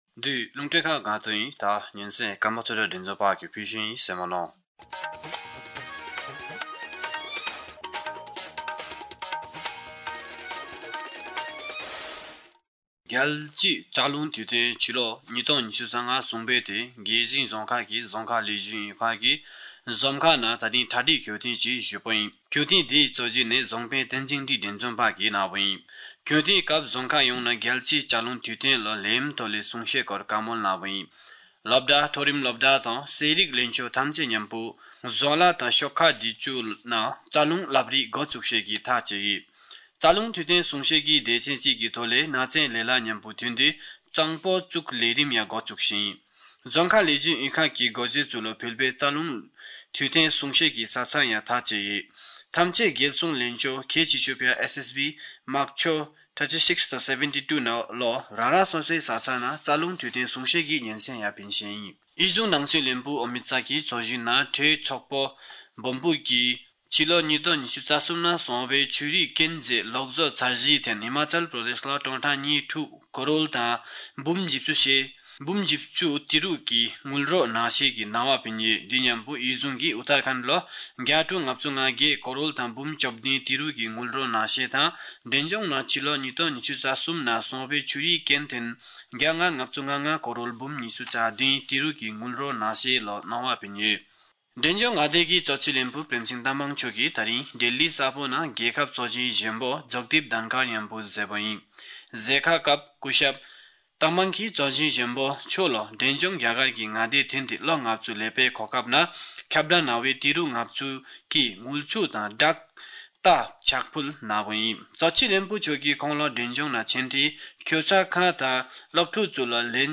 Bhutia-News-18510.mp3